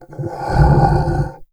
MONSTERS_CREATURES
MONSTER_Groan_02_mono.wav